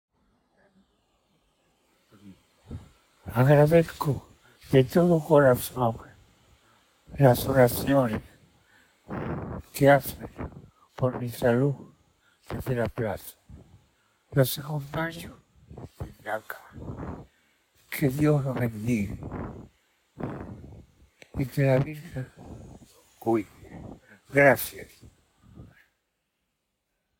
Pierwsze nagranie audio Papieża ze szpitala: podziękował za modlitwę